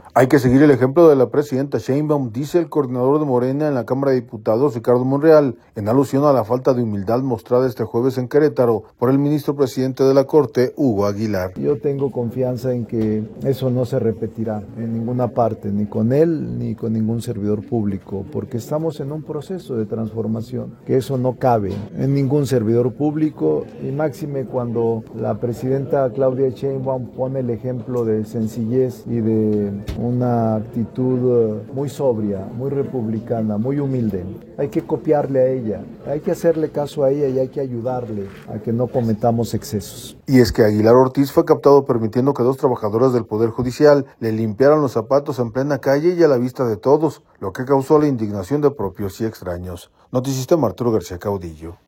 Hay que seguir el ejemplo de la presidenta Sheinbaum, dice el coordinador de Morena en la Cámara de Diputados, Ricardo Monreal, en alusión a la falta de humildad mostrada este jueves en Querétaro por el ministro presidente de la Corte, Hugo Aguilar.